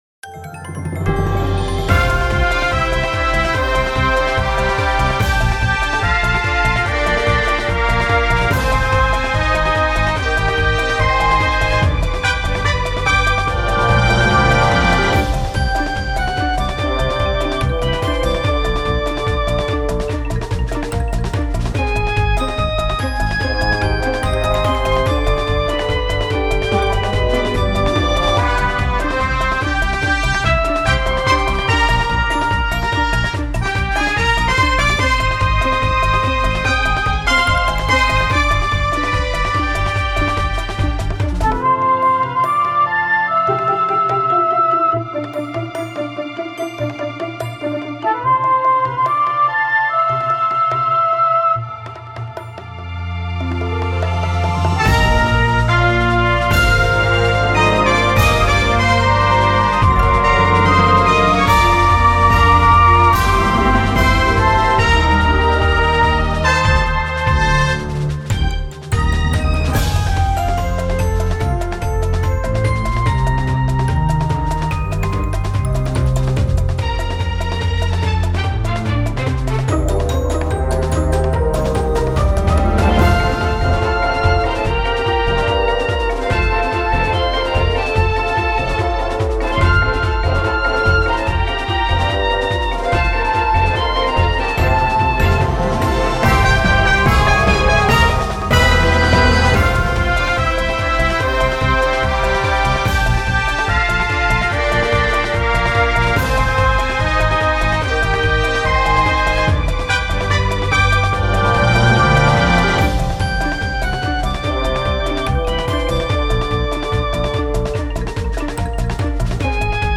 聞いたら明るくなれるような、オーケストラ風な曲を作ってみました。